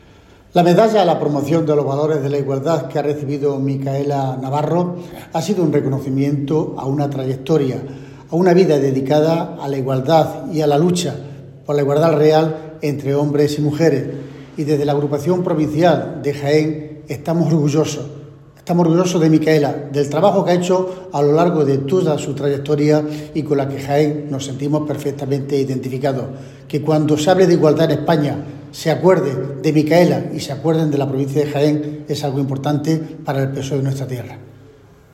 Cortes de sonido z Francisco Reyes